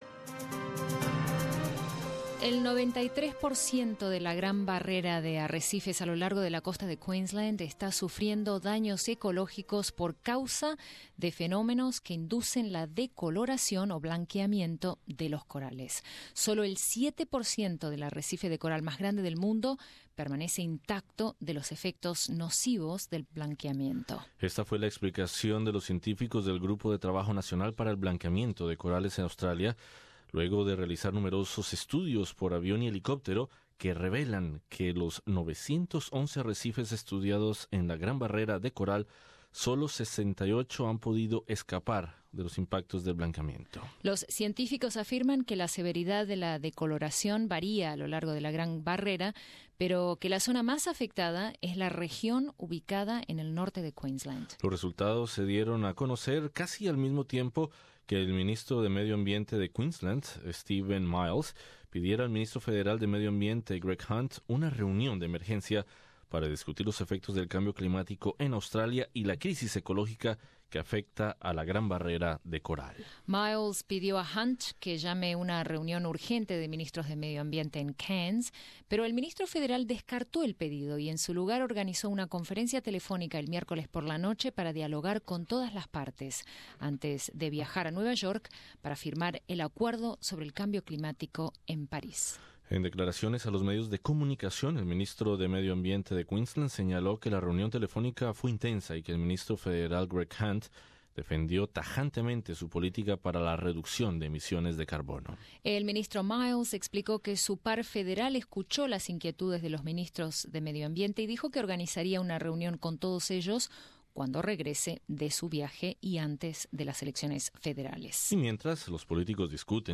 Escucha aquí nuestra entrevista